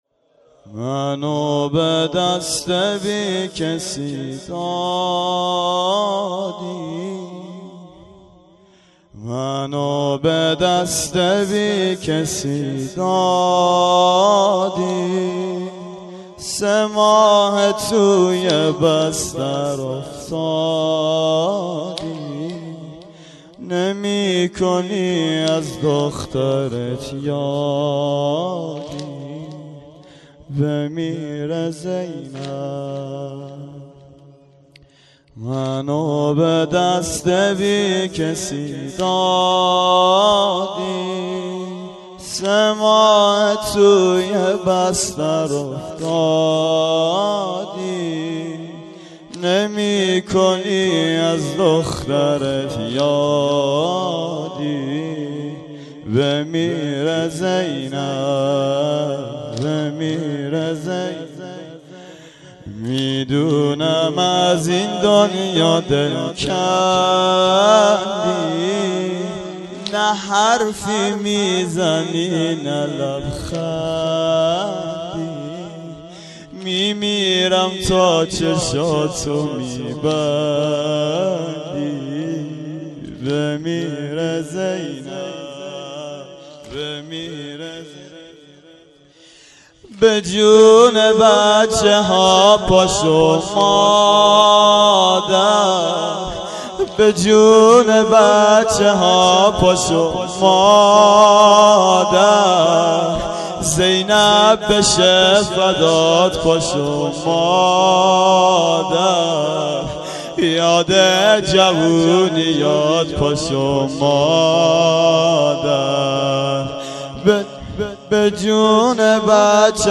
سنگین شب دوم فاطمیه دوم